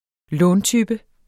Udtale [ ˈlɔːn- ]